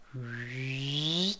snd_charge.ogg